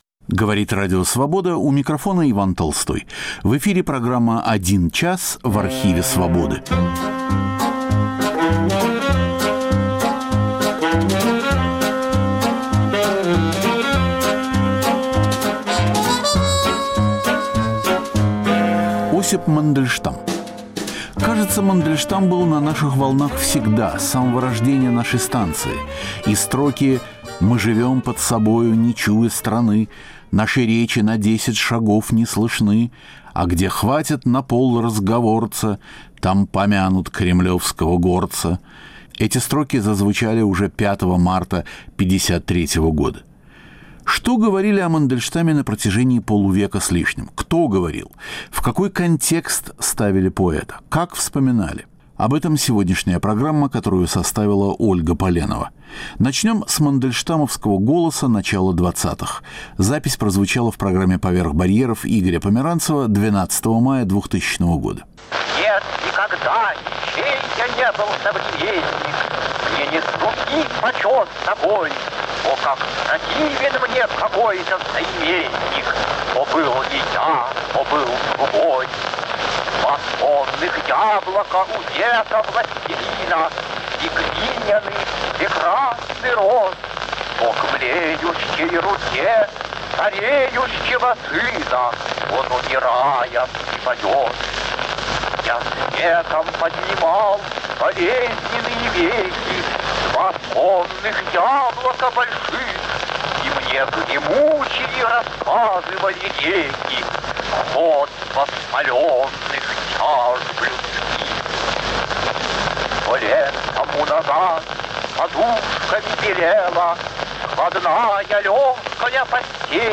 Осип Мандельштам и Надежда Мандельштам – собственными голосами - на волнах Радио Свобода. Это чудо возможно благодаря старым архивным записям. К 125-летию со дня рождения великого поэта.